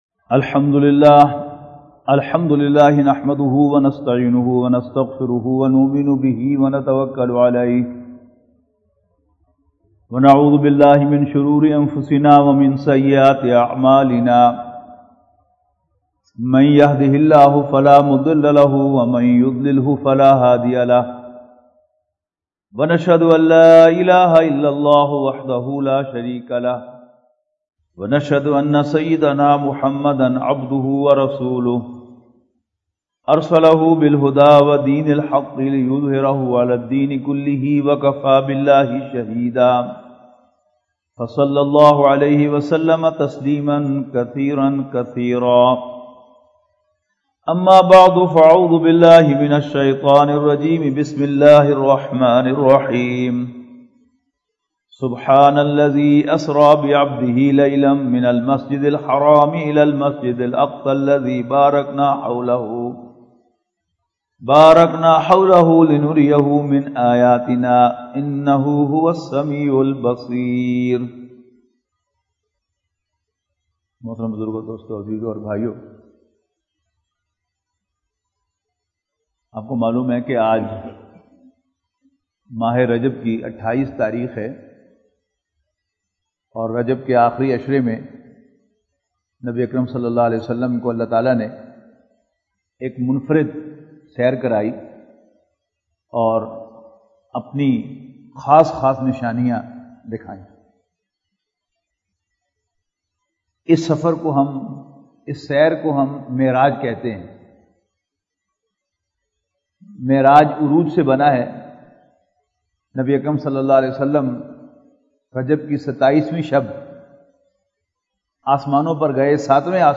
Khitab E Juma - Audio - 64 Meraj Un Nabi | Khaddam-ul-Quran